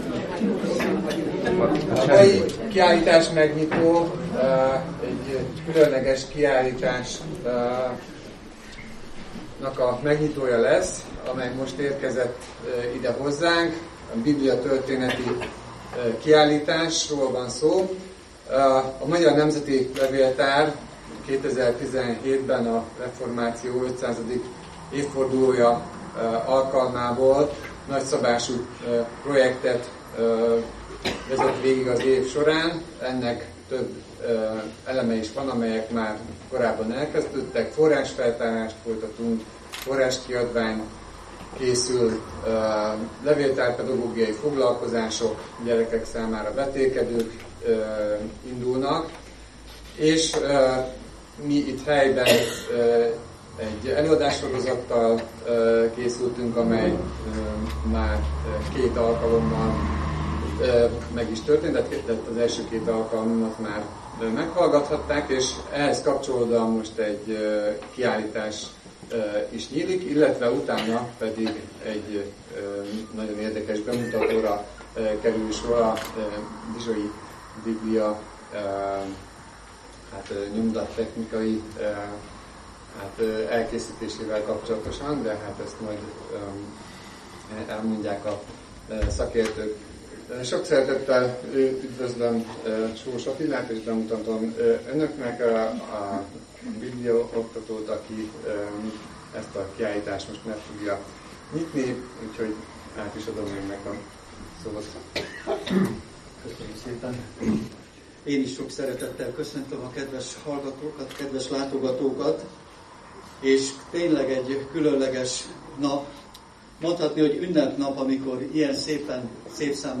Bibliatörténeti kiállításmegnyitó és előadás
A Bibliatörténeti-kiállítás megnyitója